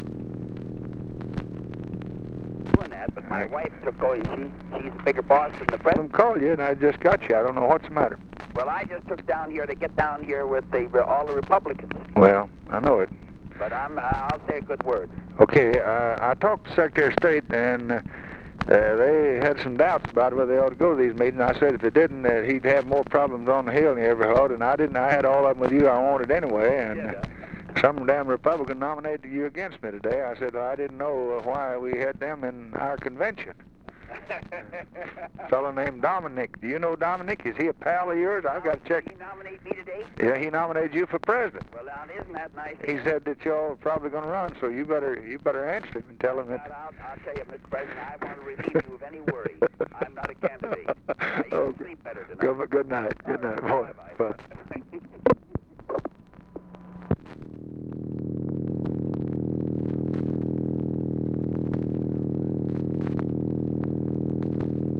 Conversation with HUBERT HUMPHREY, November 29, 1963
Secret White House Tapes | Lyndon B. Johnson Presidency